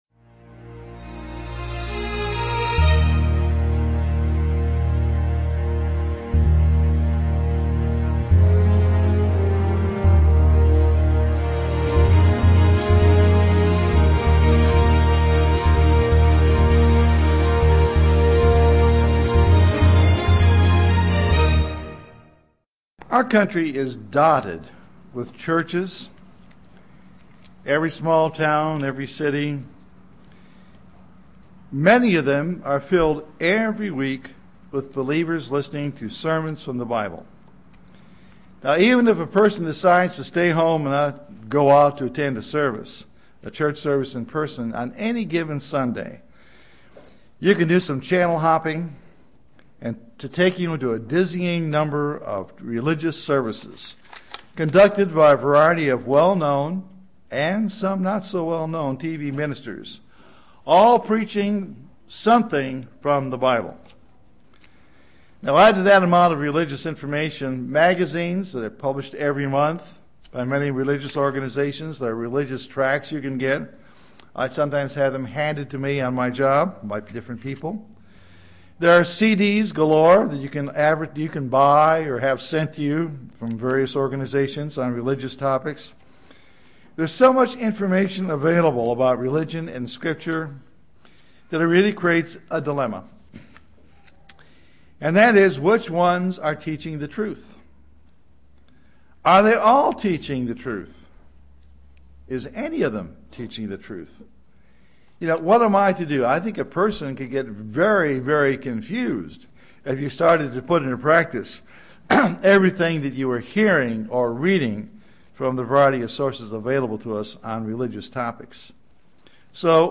UCG Sermon Studying the bible?
Given in Chattanooga, TN